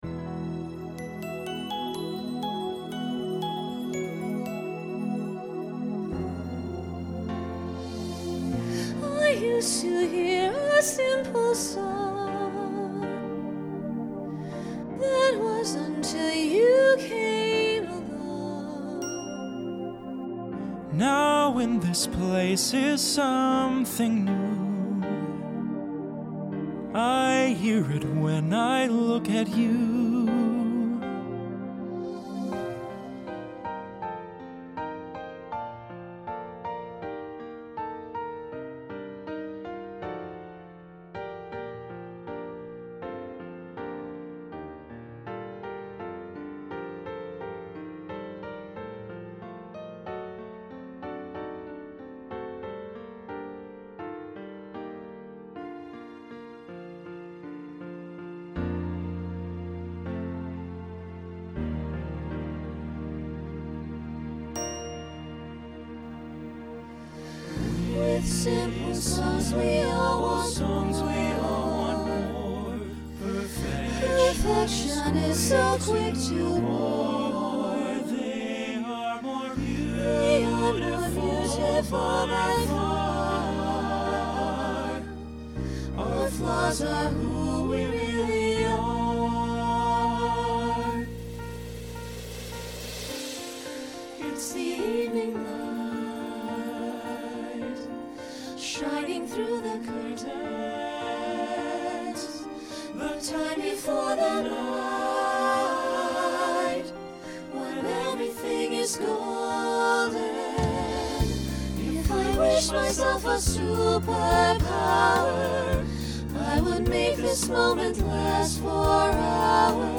Instrumental combo Genre Pop/Dance
Ballad , Solo Feature , Story/Theme Voicing SATB